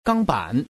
Index of /qixiGame/release/guanDan/jsGuangDian/assets/res/zhuandan/sound/woman/